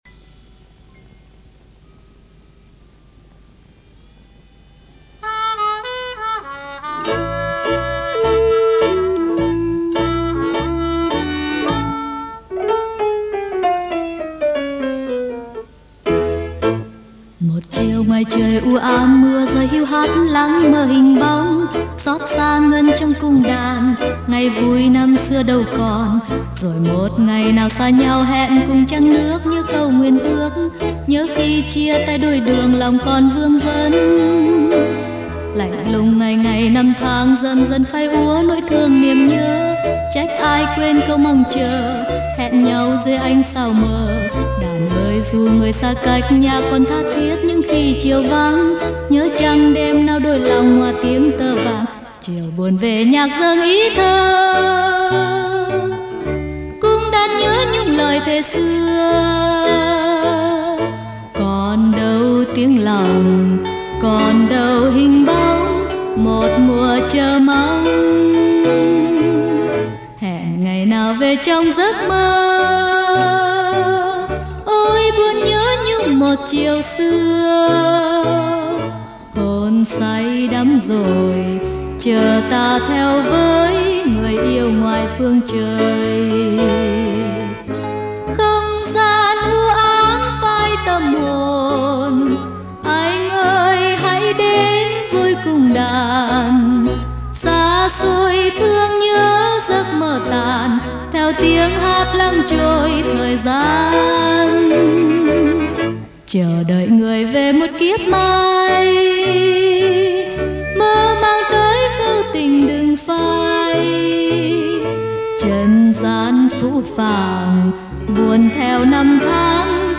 Tinh ca tien chien Pre 75